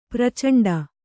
♪ pracaṇḍa